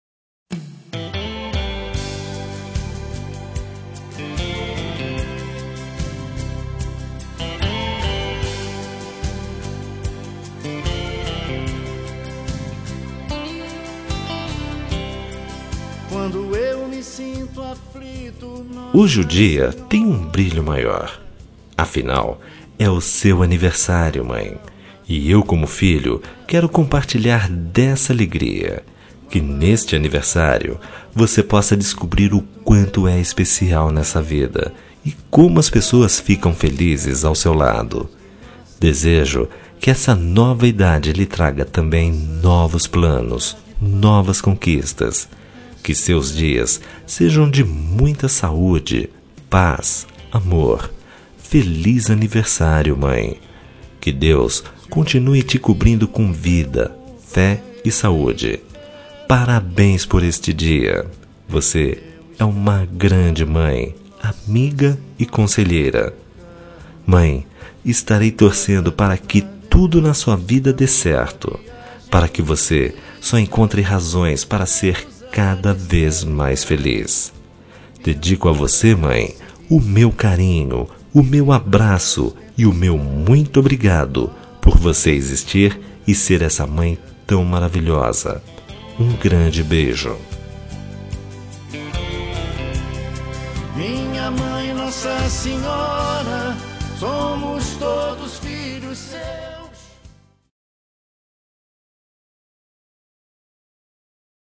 Telemensagem de Aniversário de Mãe – Voz Masculina – Cód: 1440